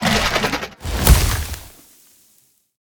Sfx_creature_rockpuncher_deathshort_01.ogg